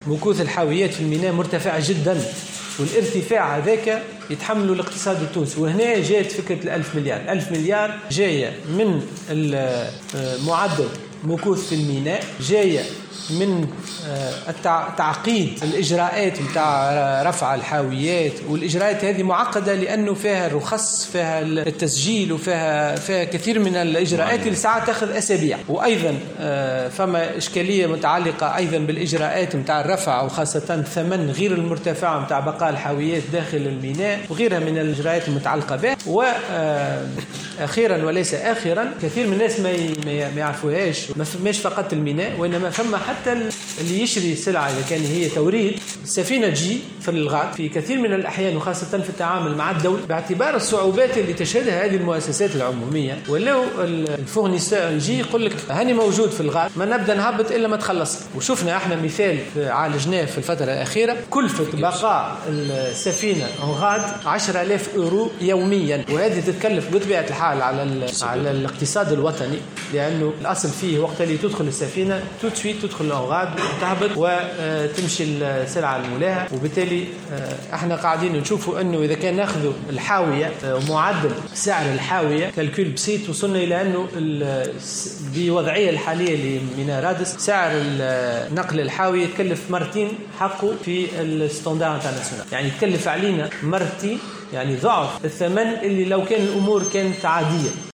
وبيّن معروف خلال جلسة استماع له اليوم الجمعة 12 جوان 2020 في لجنة تنظيم الإدارة وشؤون القوات الحاملة للسلاح ، أن طول مدة إنتظار السفن في الميناء وبطء إجراءات الرفع وتراجع جاهزية معدات الشحن و الترصيف وغياب منظومات صيانة متطورة ، اضطرّ الوزارة إلى اتخاذ جملة من الإجراءات لتسريع العمل بالميناء منها خاصة العمل دون توقف على مدار اليوم .